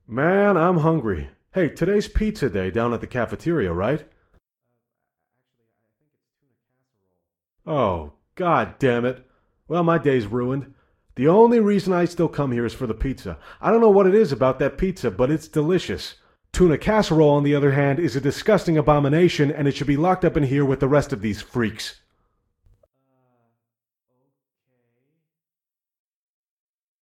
Conversation3a.ogg